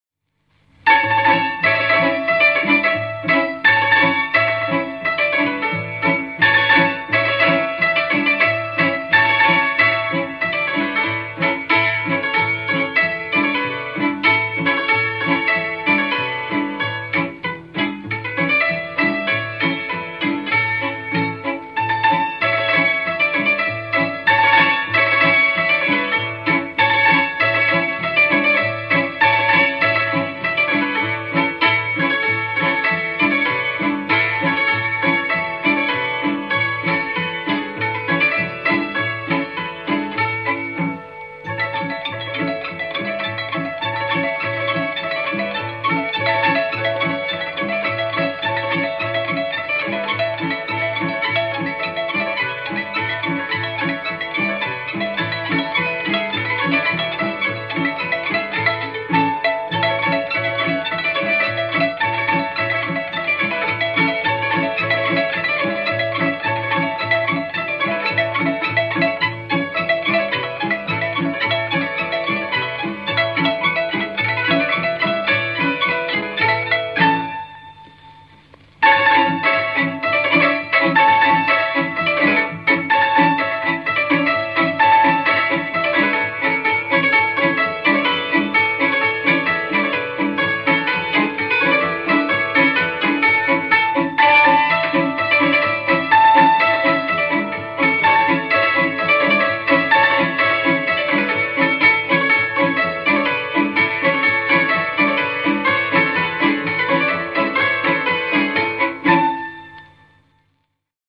Hangszer Zenekar
Helység Sopron
cimbalom
cselló